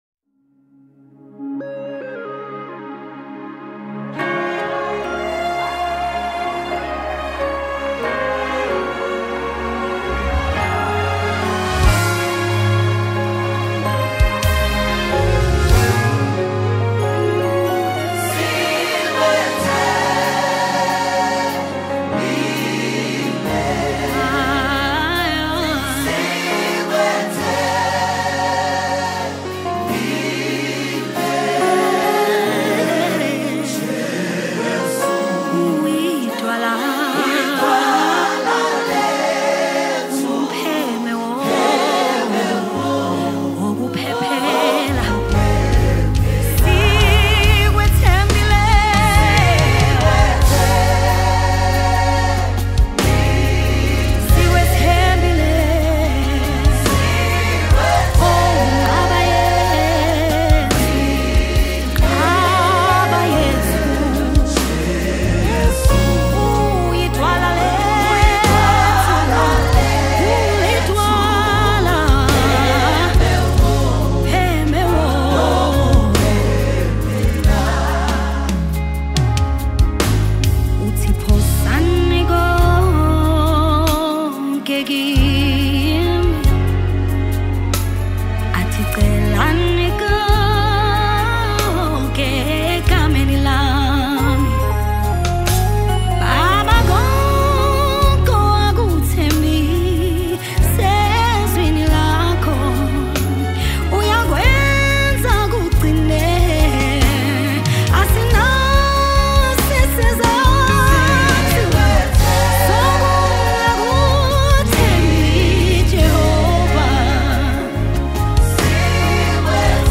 South African Gospel
Genre: Gospel/Christian.